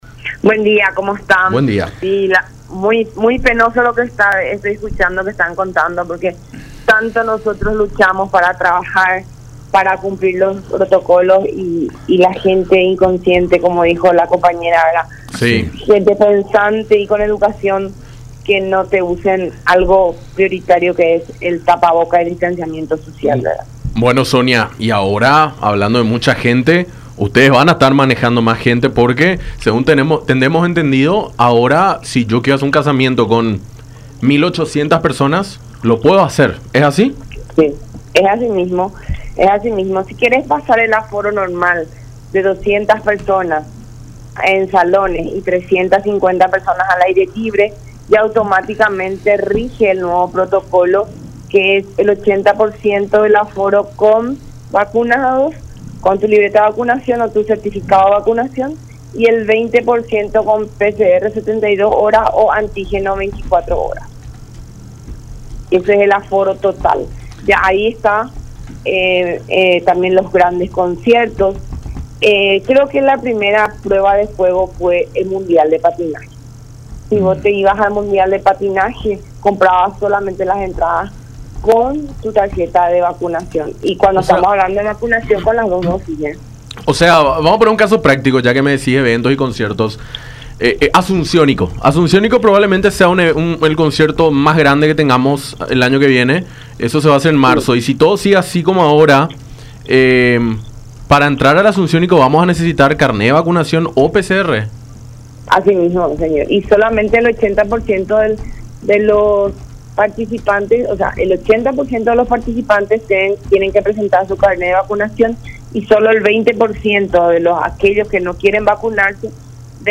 en conversación con Enfoque 800 por La Unión